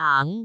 speech
syllable
pronunciation
aang2.wav